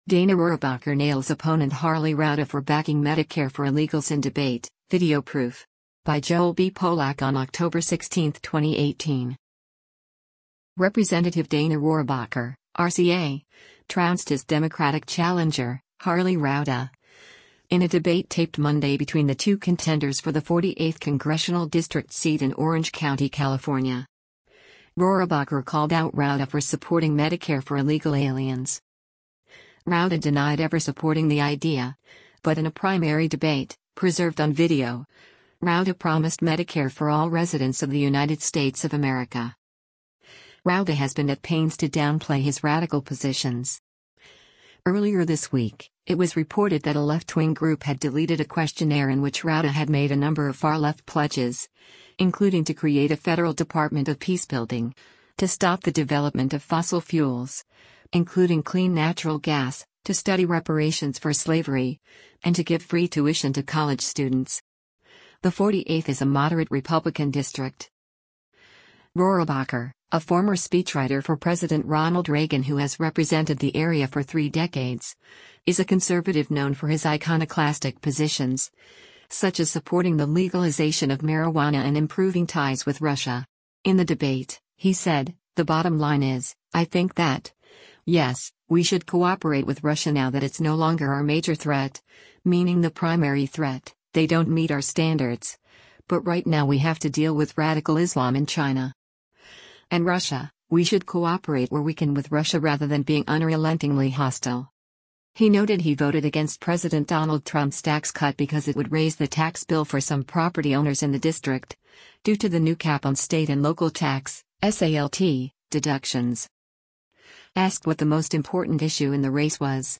Dana Rohrabacher - Harley Rouda Debate
Rep. Dana Rohrabacher (R-CA) trounced his Democratic challenger, Harley Rouda, in a debate taped Monday between the two contenders for the 48th congressional district seat in Orange County, California. Rohrabacher called out Rouda for supporting Medicare for illegal aliens.